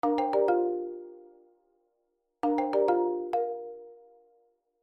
• Sinal sonoro de todas as ligações:
toque.mp3